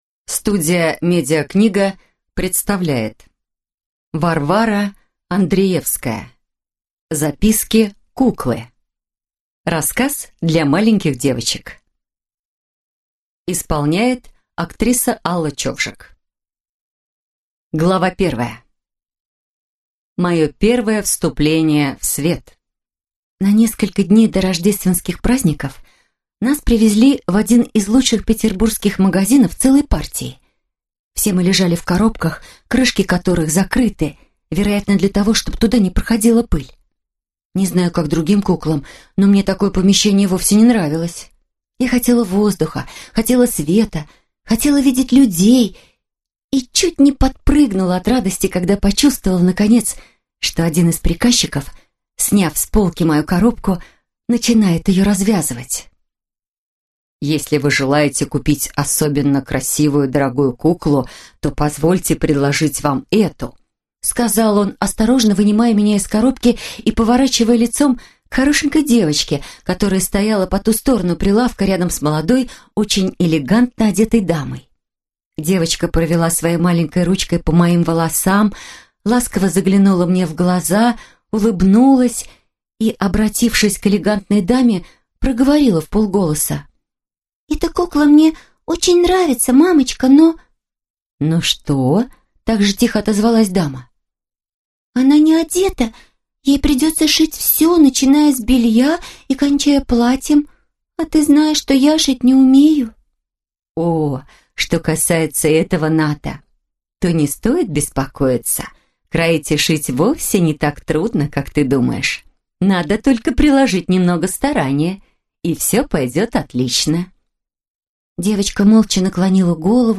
Аудиокнига Записки куклы | Библиотека аудиокниг